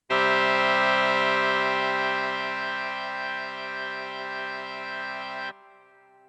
Aufnahmen vom Kopfhörerausgang
Aber die Aufnahmen werden vom Notebook nach ca. 2 Sekunden im Pegel deutlich abgesenkt.
Kurze Töne erklingen in der vollen Lautstärke. Sobald ich einen Ton länger stehen lasse, wird der Pegel zurückgeregelt.
gedrückten und gehaltenen Akkord.
akkord.mp3